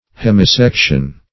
Search Result for " hemisection" : The Collaborative International Dictionary of English v.0.48: Hemisection \Hem`i*sec"tion\, n. (Anat.)